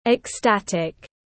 Vô cùng hạnh phúc tiếng anh gọi là ecstatic, phiên âm tiếng anh đọc là /ɪkˈstætɪk/
Ecstatic /ɪkˈstætɪk/